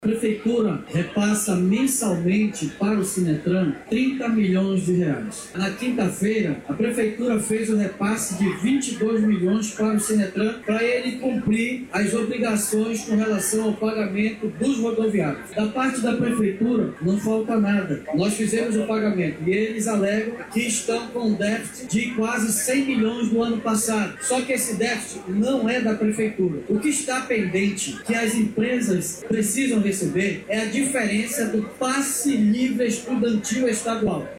Durante uma entrevista coletiva, o prefeito de Manaus, Davi Almeida, disse que os repasses ao Sinetram estão em dia e que há pendências por parte do Estado.